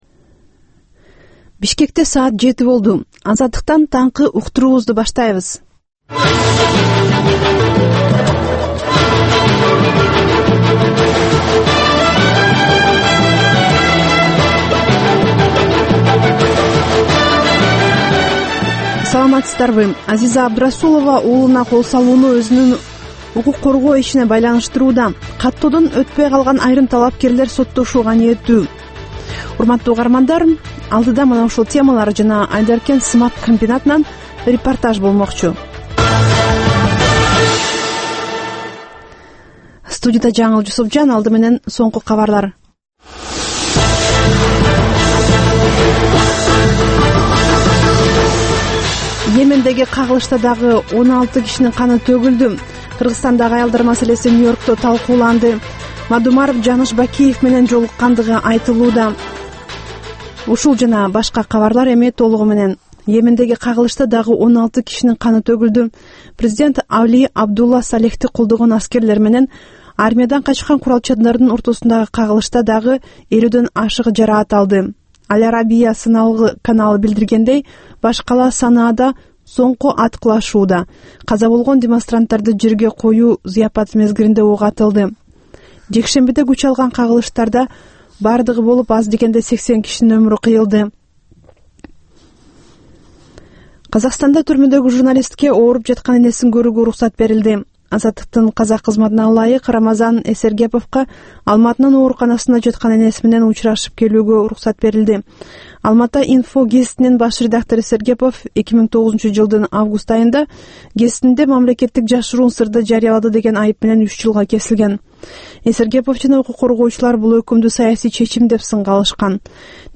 Таңкы 7деги кабарлар